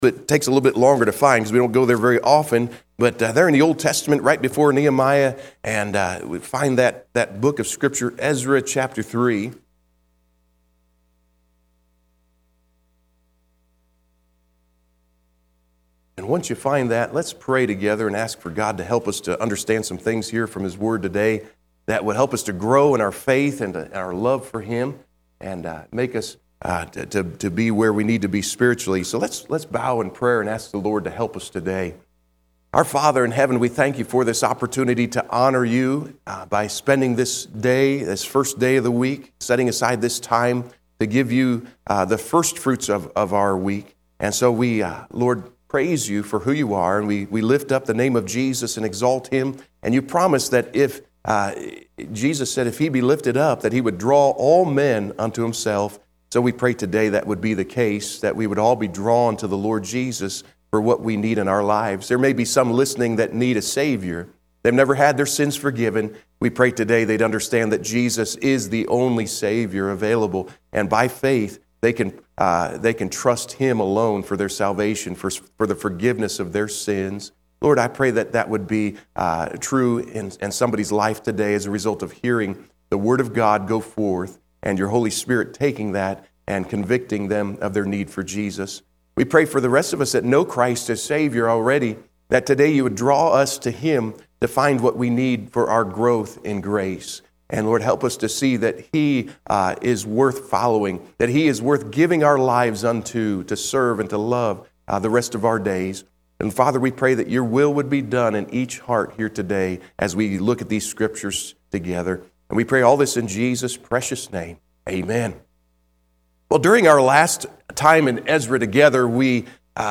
Sunday, March 23, 2025 – AM Service – Making Some Noise – Ezra Chapter 3 – Coastal Shores Baptist Church